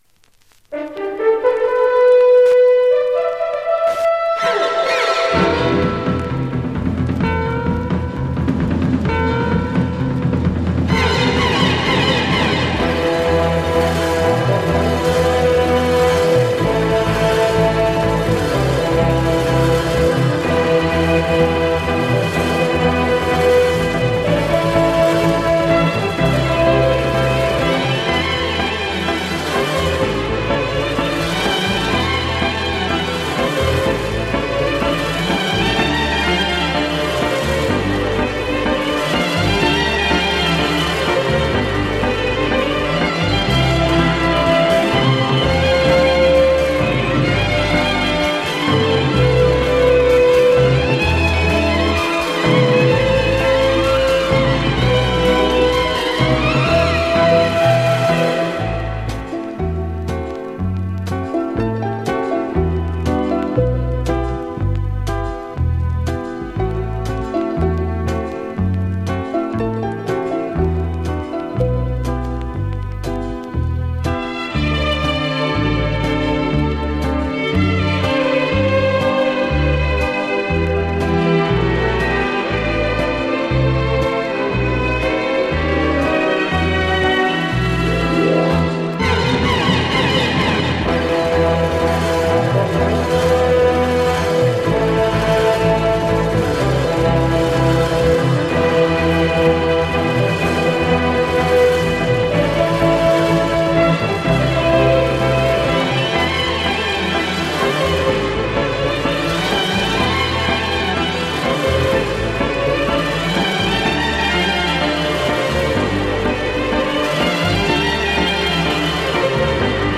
French library easy groove
Incredible strange soundtrack music on both sides.